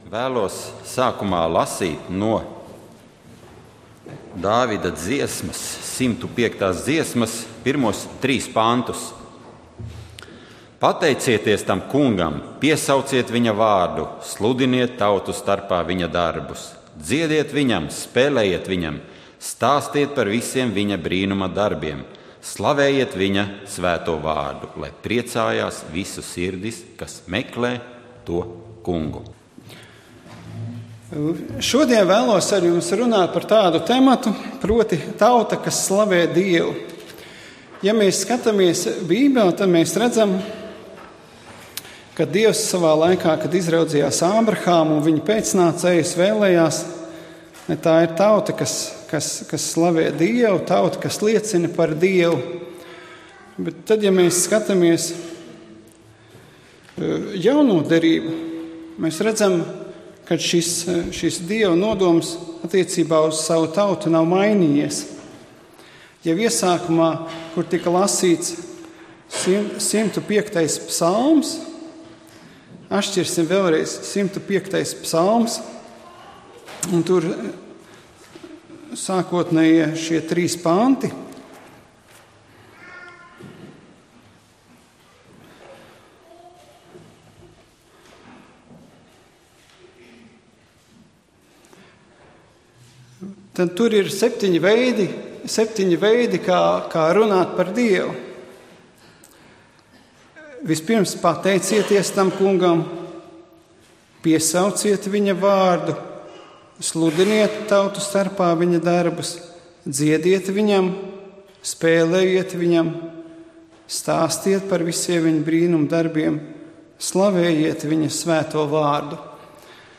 Dievkalpojums 11.07.2015: Klausīties
Svētrunas